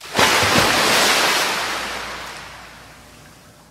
airutils_touch_water.ogg